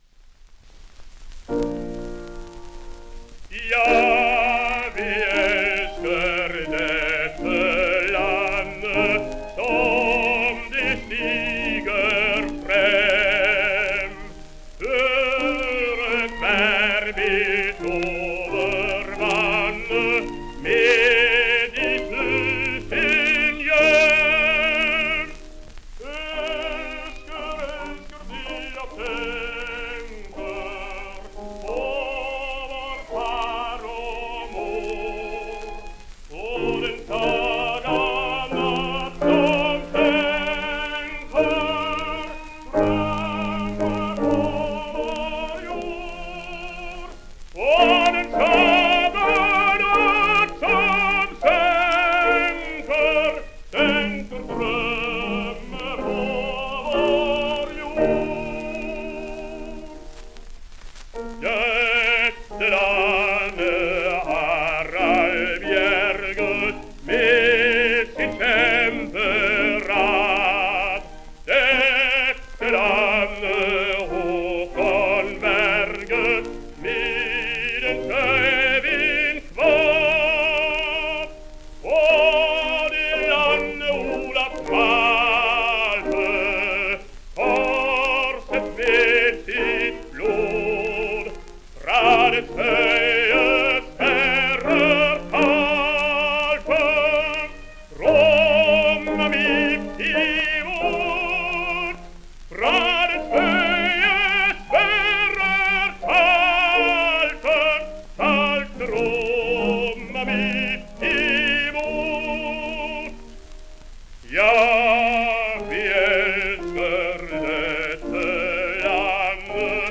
The Norwegian national anthem